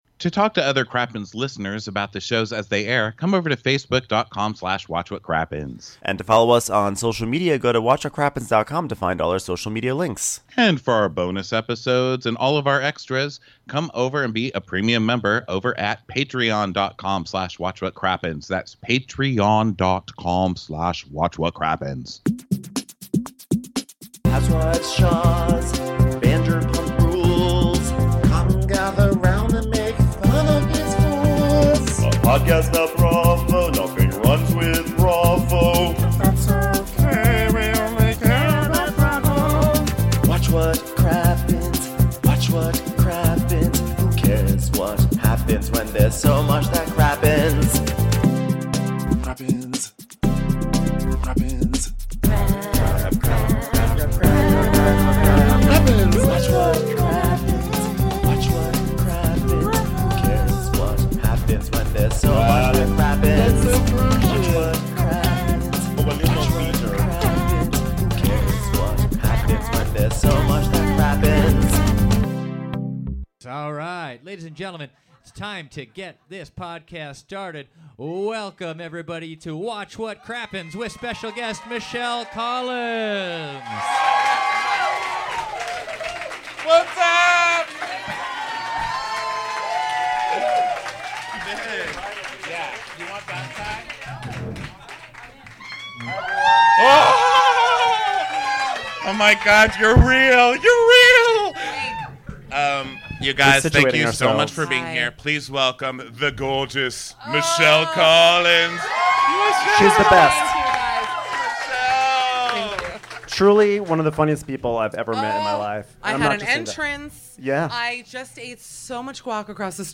#455: Live at the Improv!